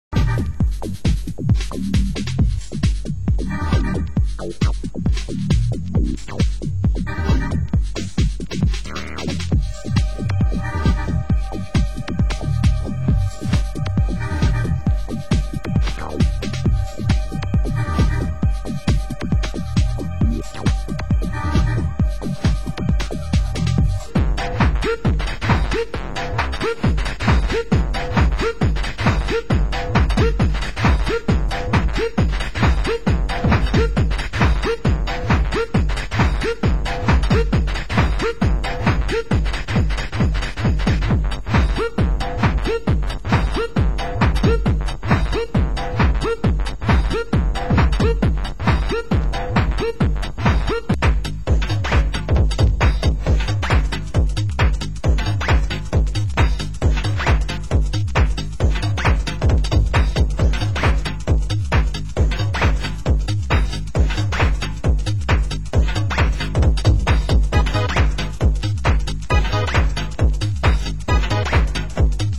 Genre Euro Techno